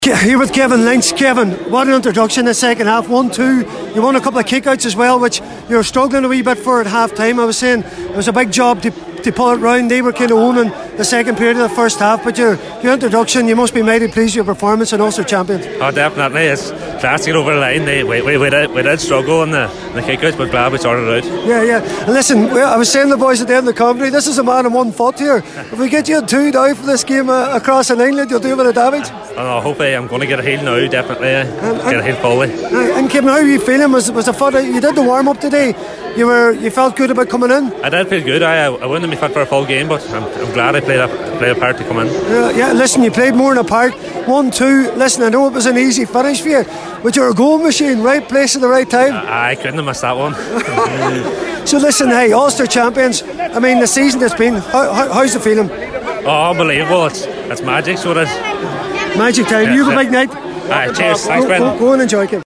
Reaction